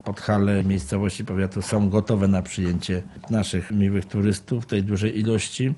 starosta.mp3